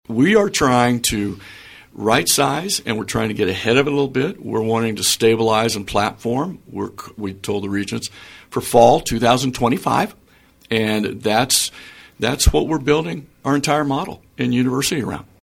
Emporia State University President Ken Hush says a transformation is fully underway on campus, and he offered his perspective on KVOE’s Morning Show on Thursday.